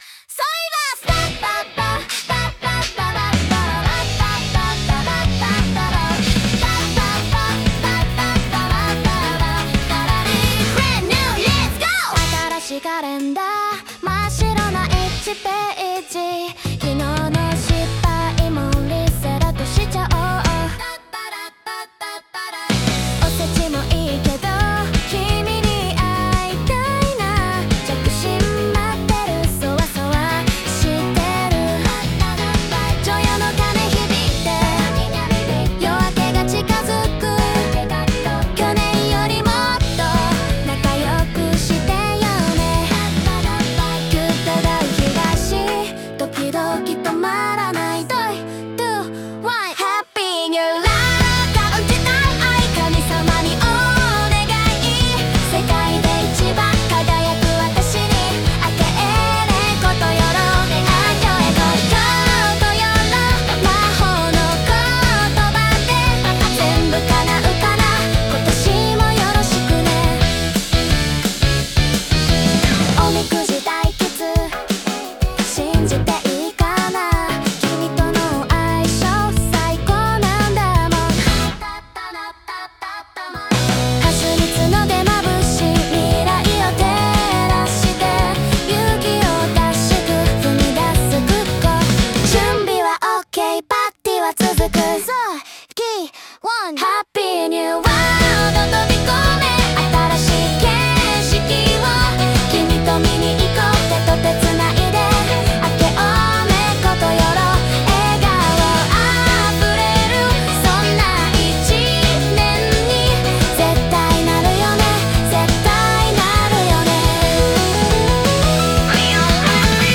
这份祝福由胶带回声、模拟振幅与城市的呼吸录制。
声波祝福片段 建议佩戴耳机聆听,感受低频与钟鸣的交织。